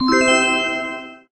magic_harp_short.ogg